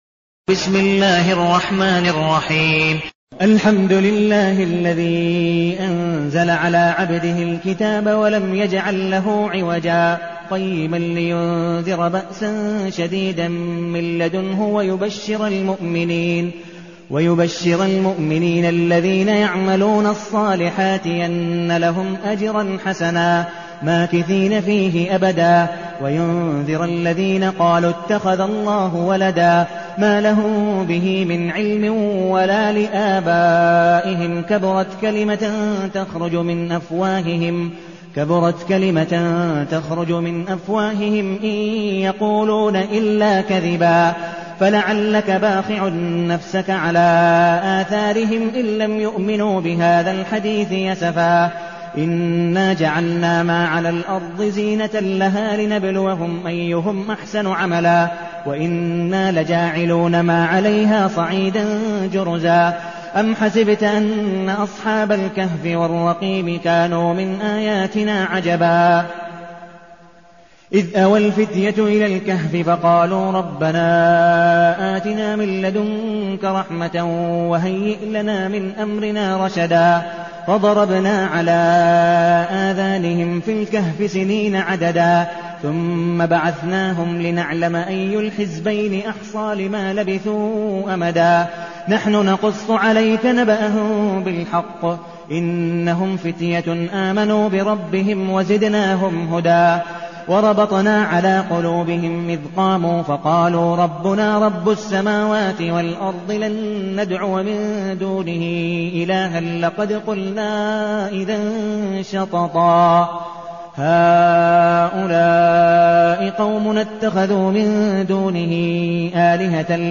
المكان: المسجد النبوي الشيخ: عبدالودود بن مقبول حنيف عبدالودود بن مقبول حنيف الكهف